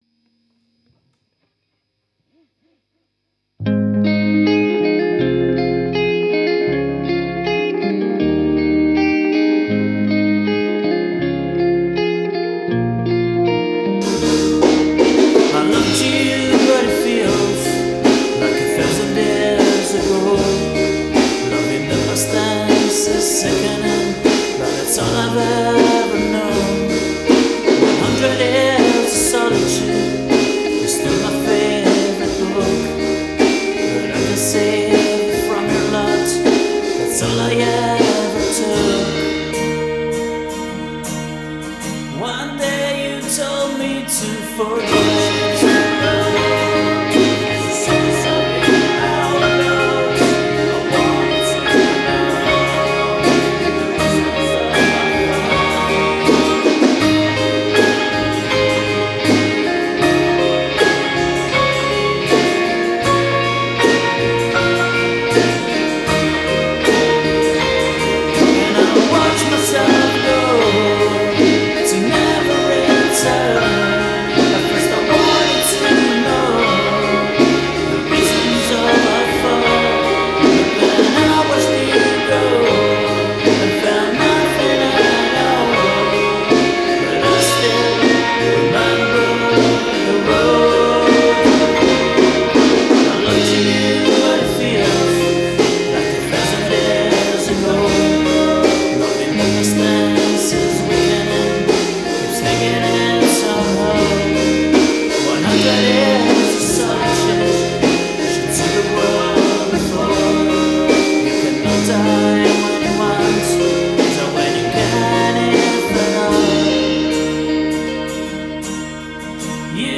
vocals, guitars, bass, drums, keyboards